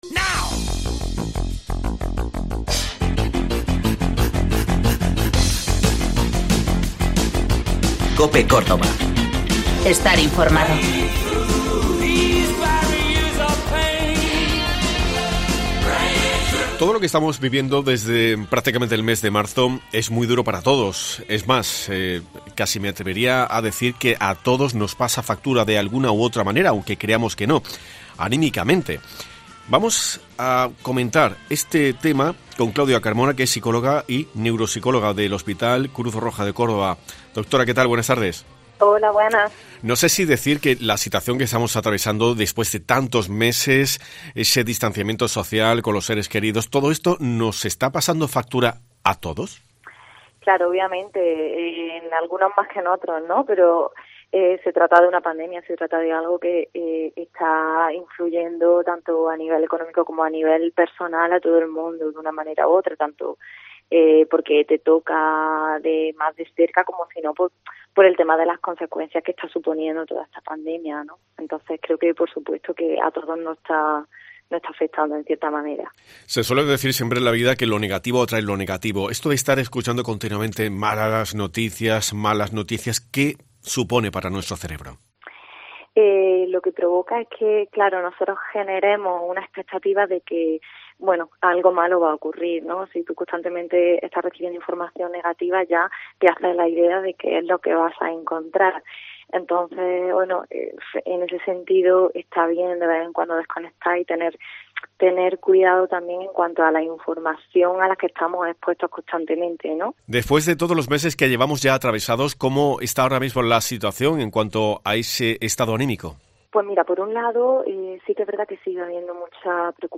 Hemos hablado con